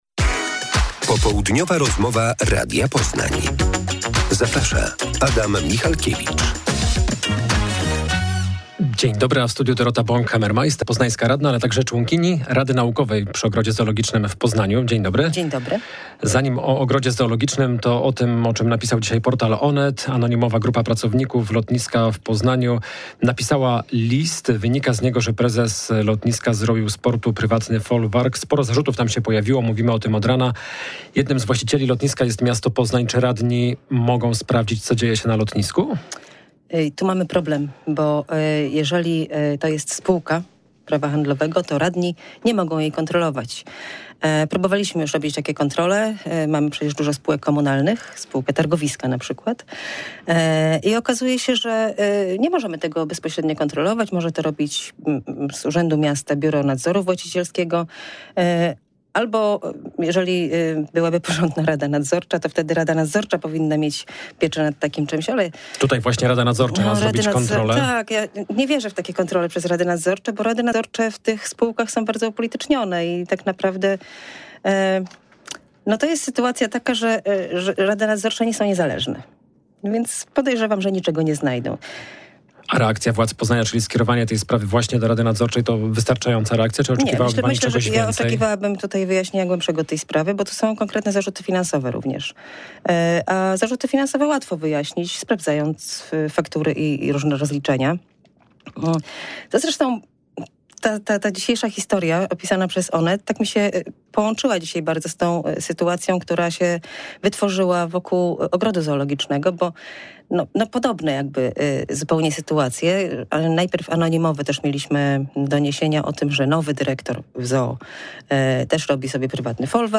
Popołudniowa rozmowa Radia Poznań – Dorota Bonk-Hammermeister